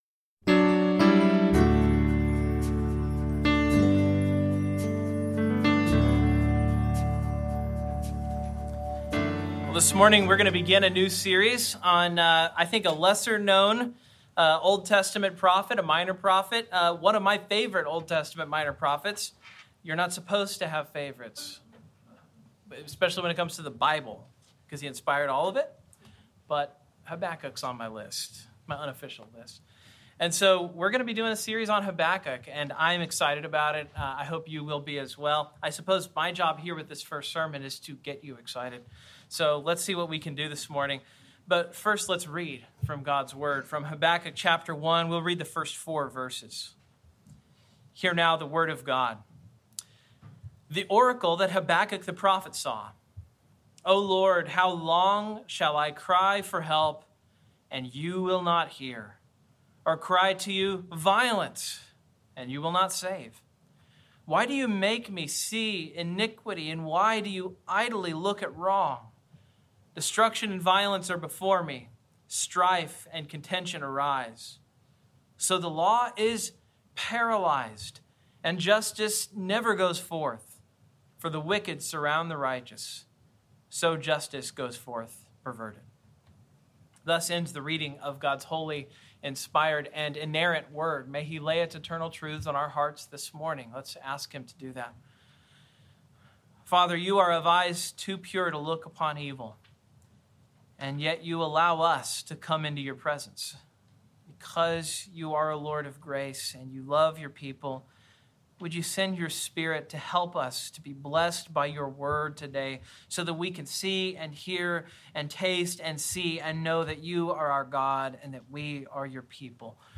February 7, 2021 The Paralyzed Law Series: Habakkuk Passage: Habakkuk 1:1-4 Service Type: Morning Sermon Outline Main Point: A mature faith trusts humbly in God’s design for establishing righteousness in the earth.